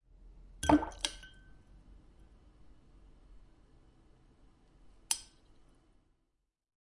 水声》第一卷 " 水花；水滴2
描述：水溅落入玻璃。 用Zoom H1记录。
标签： 效果 飞溅 水花 飞溅 液滴 FX 液滴 弗利
声道立体声